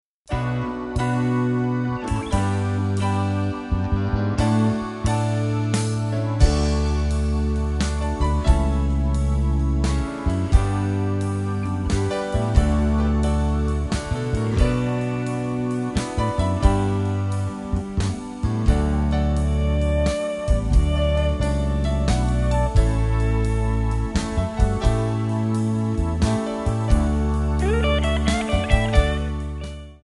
Backing track files: 1970s (954)
Buy Without Backing Vocals